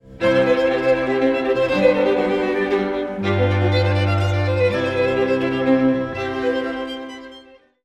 mp3Bologne, Joseph, String Quartet No. 1 mvt. II., Rondeau Tempo di Menuetto Gratioso, mm.37-40